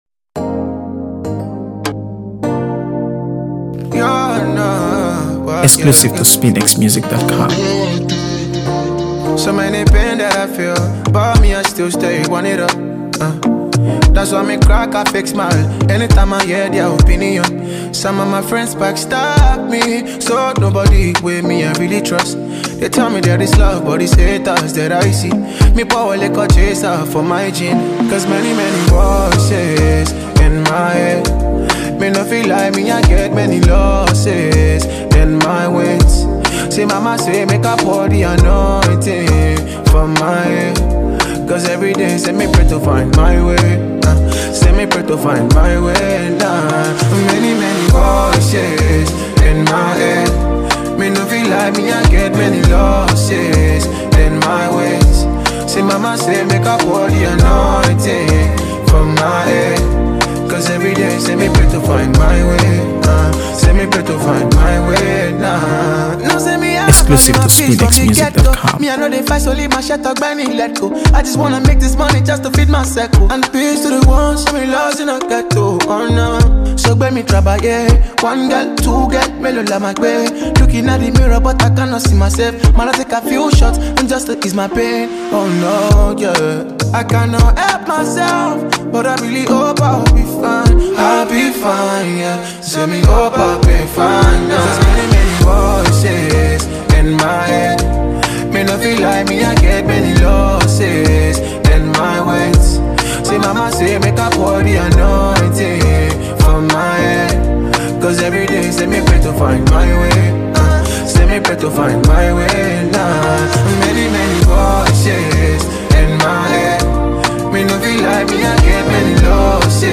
AfroBeats | AfroBeats songs
Known for his smooth vocals and honest storytelling
It’s thoughtful, melodic, and emotionally rich.